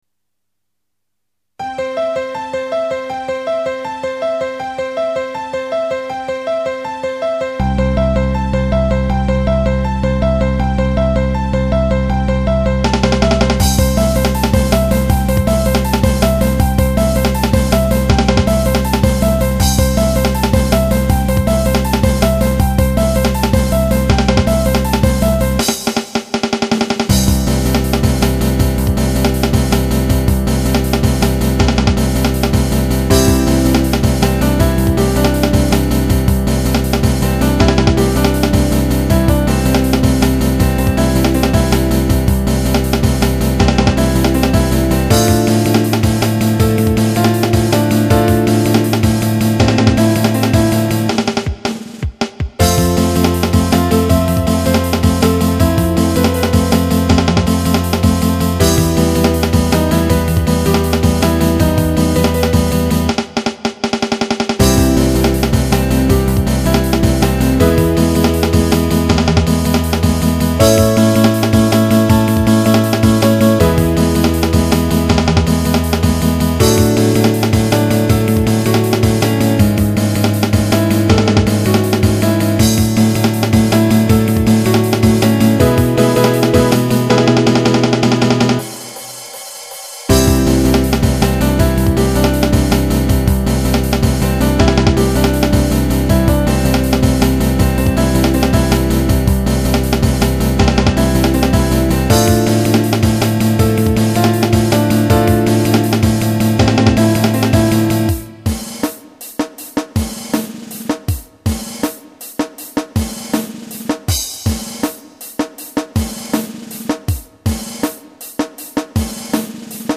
Genre：drum'n'bass